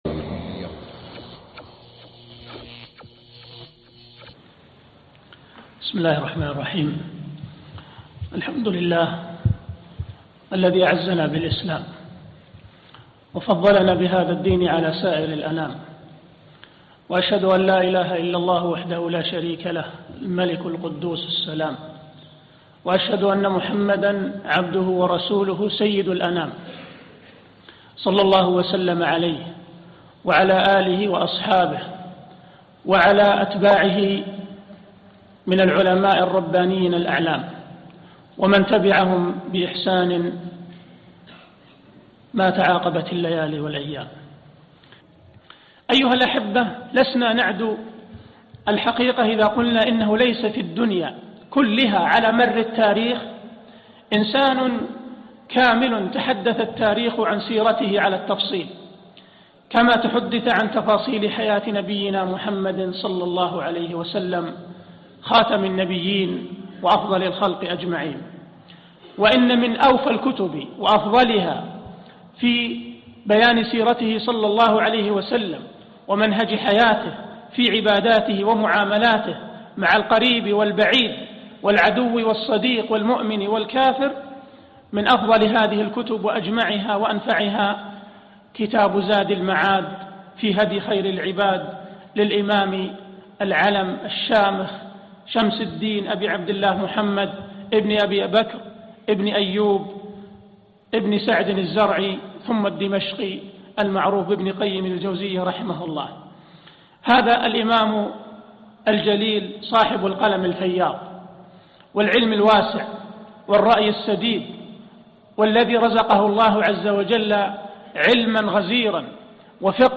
محاضرة اليوم